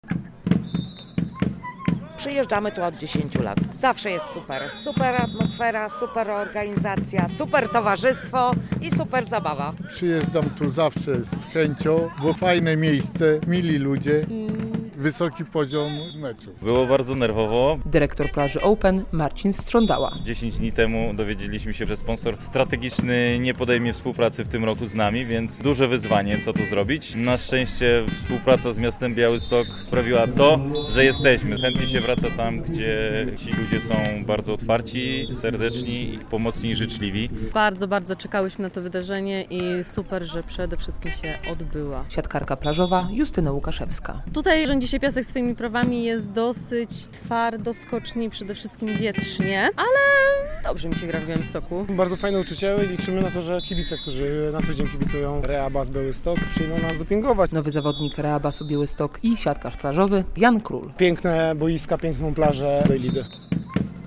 Plaża Open - relacja